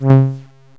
katana.wav